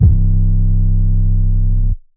Major 808 5.wav